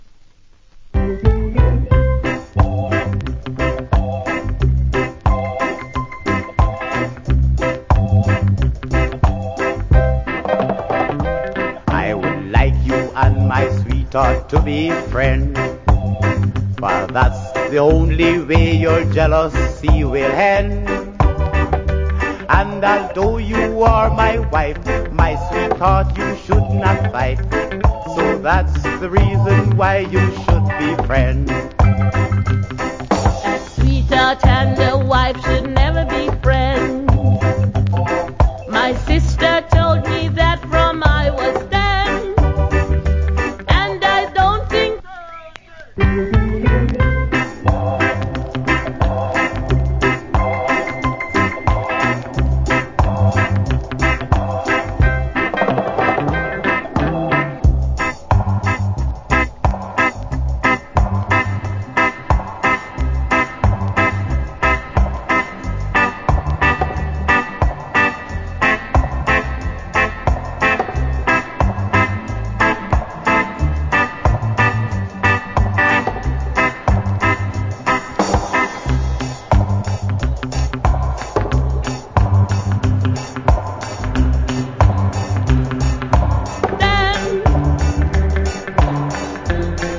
Good Duet Reggae Vocal.